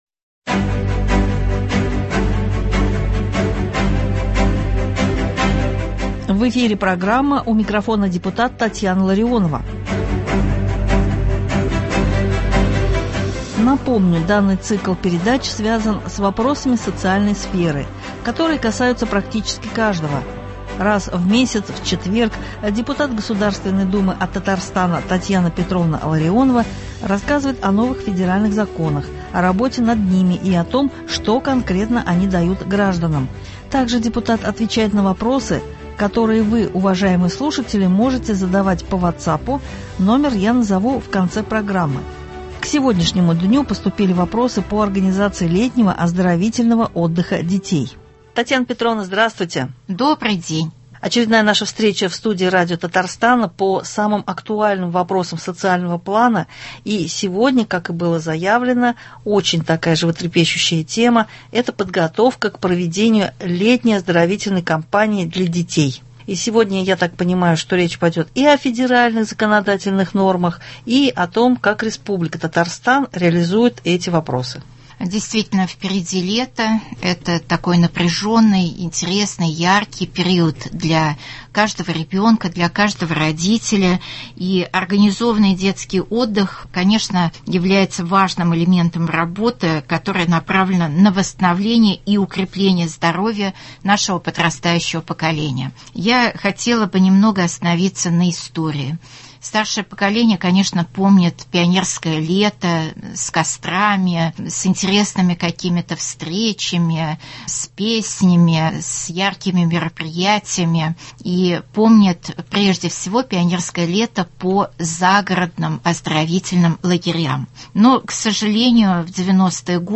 У микрофона депутат Татьяна Ларионова (06.04.23)
Раз в месяц в четверг депутат Государственной Думы от Татарстана Татьяна Петровна Ларионова рассказывает о новых федеральных законах, о работе над ними и о том, что конкретно они дают гражданам. Также депутат отвечает на вопросы, которые вы, уважаемые слушатели , можете задавать по вотсапу, номер я назову в конце программы . К сегодняшнему дню поступили вопросы по организации летнего оздоровительного отдыха детей.